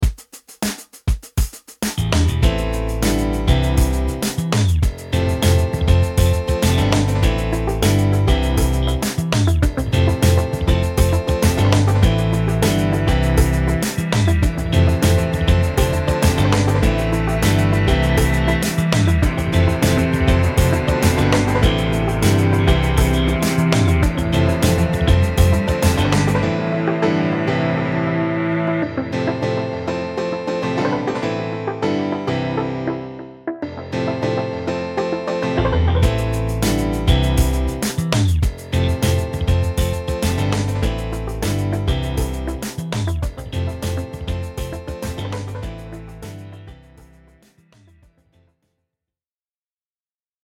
タグ: あやしい